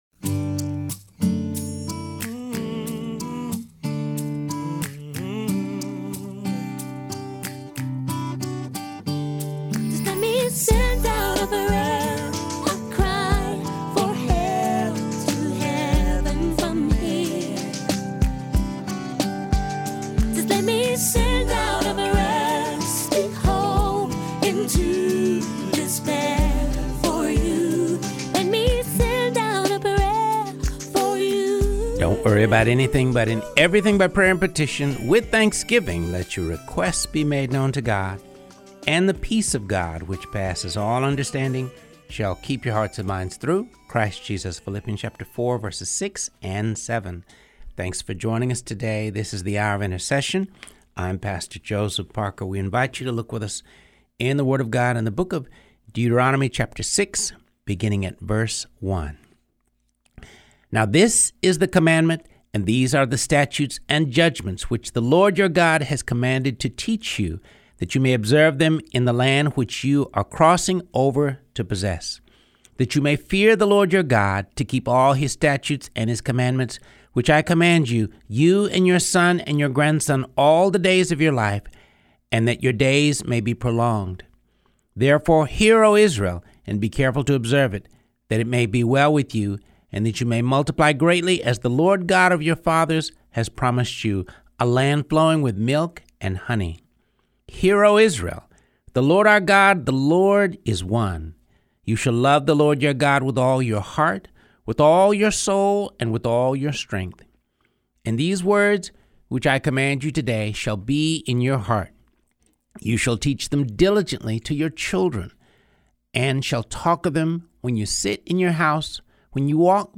Along the way, you'll hear a half dozen speeches from the 10 Commandments Project.